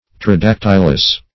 Tridactylous \Tri*dac"tyl*ous\, a.
tridactylous.mp3